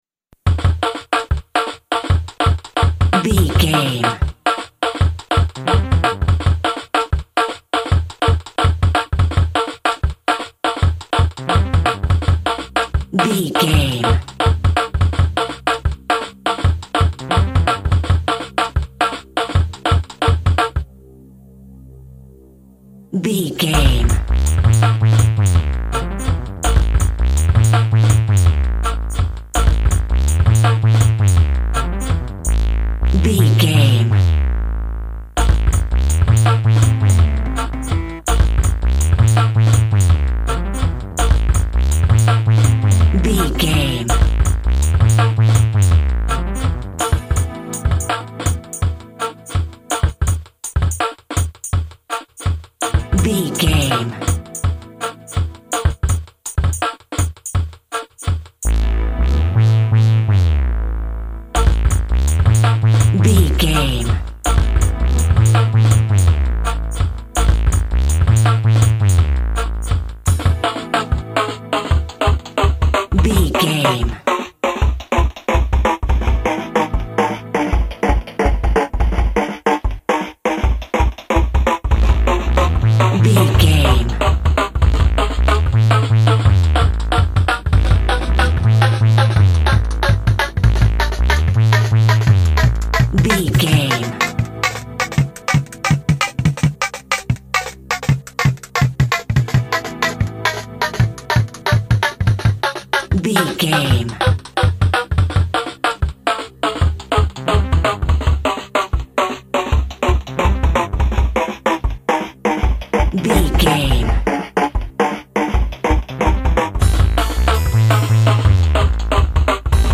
Aeolian/Minor
Fast
futuristic
dark
frantic
energetic
synthesiser
drum machine
synth lead
synth bass